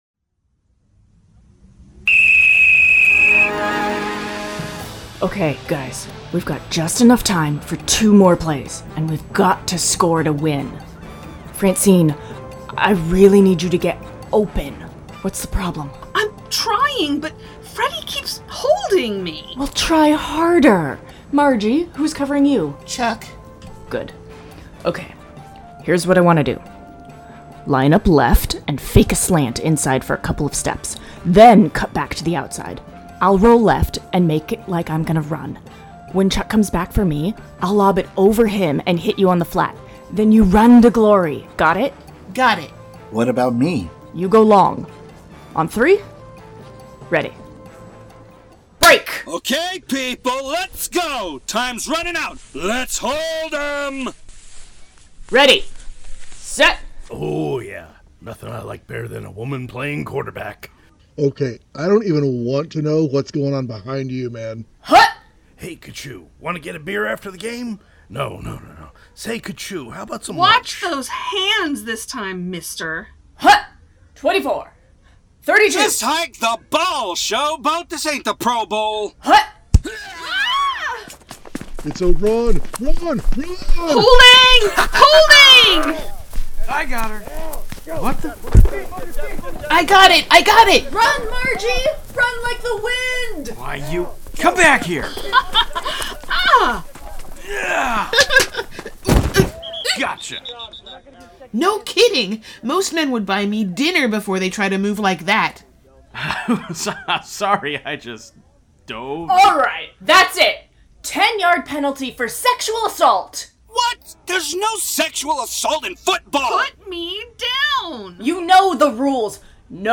Strangers In Paradise – The Audio Drama – Book 8 – My Other Life – Episode 9 – Two True Freaks